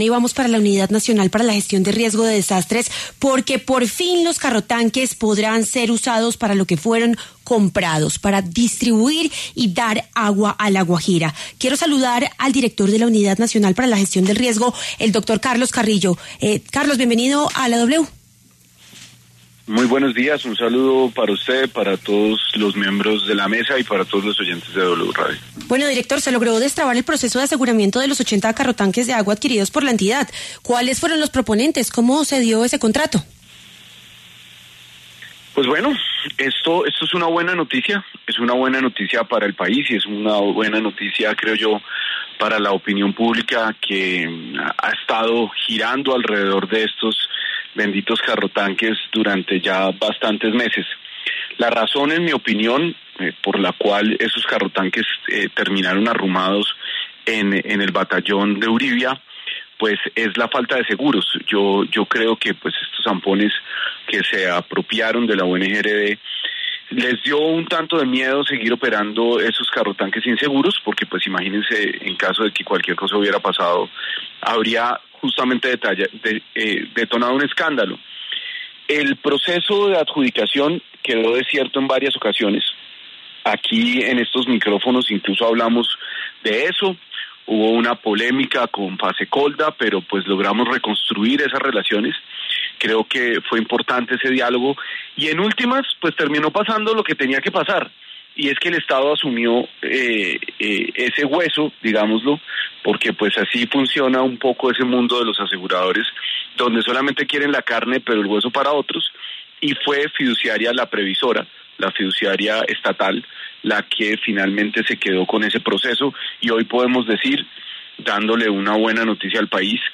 El director de la UNGRD, Carlos Carrillo, anunció esta esperada noticia durante una entrevista en W Radio, donde explicó los obstáculos que habían mantenido los carrotanques inactivos y los esfuerzos realizados para desbloquear el proceso.